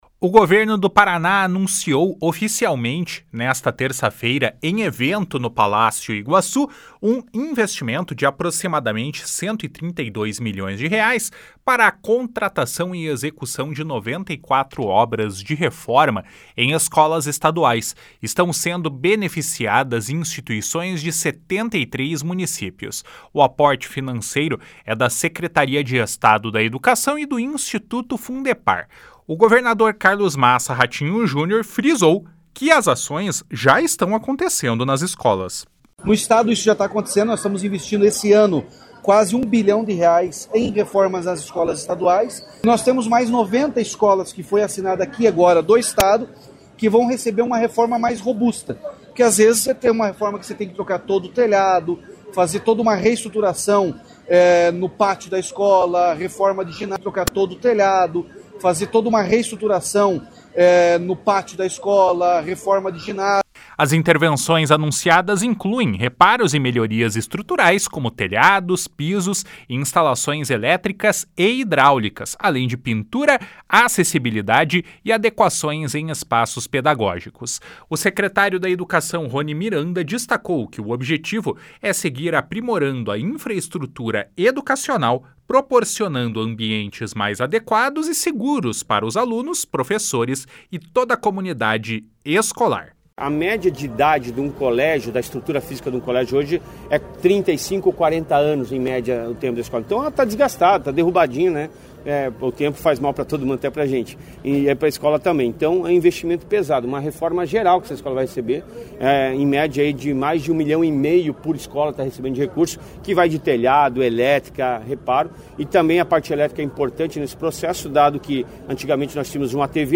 O Governo do Paraná anunciou oficialmente nesta terça-feira, em evento realizado no Palácio Iguaçu, um investimento de aproximadamente 132 milhões de reais para a contratação e execução de 94 obras de reforma em escolas estaduais.
// SONORA RATINHO JUNIOR //